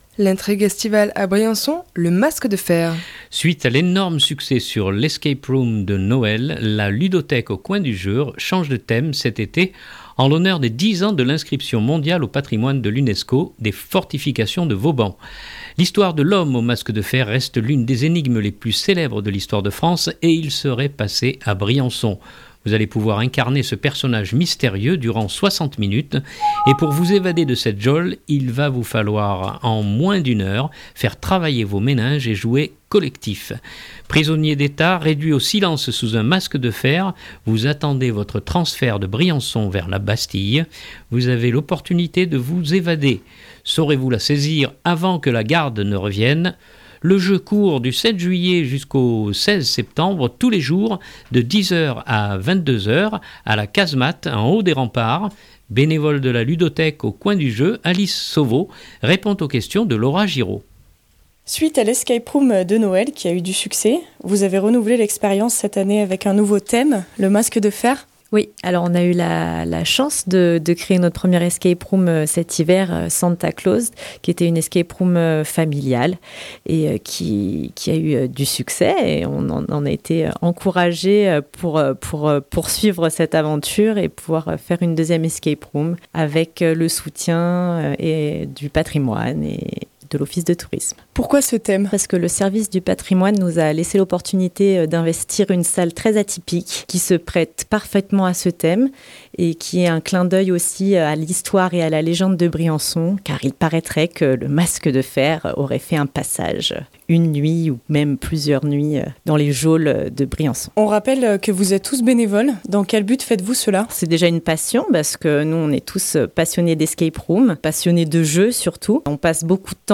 Le jeu court du 7 juillet au 16 septembre tous les jours de 10h à 22h à la casemate, en haut des remparts. Bénévole de la ludothèque au coin du jeu